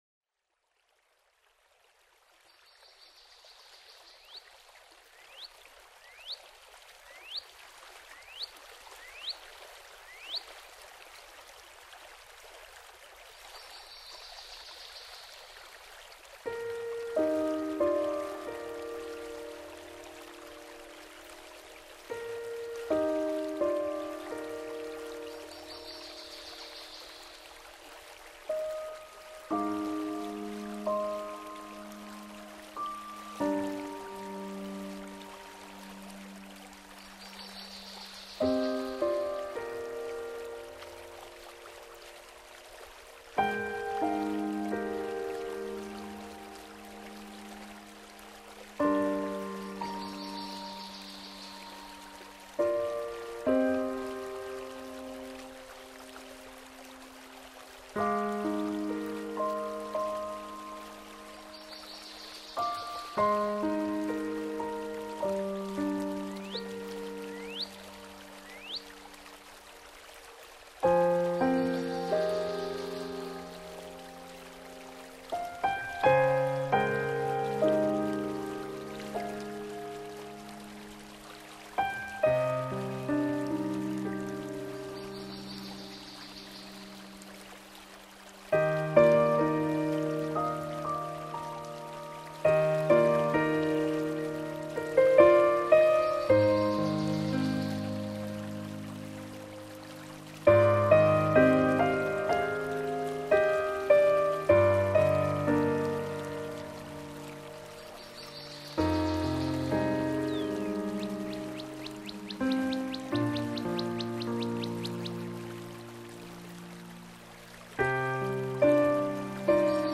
聆赏轻盈钢琴里的婉约旋律溢洒绿叶间
轻盈地与钢琴的婉约旋律自在和奏著，
交融而出的恬静氛围，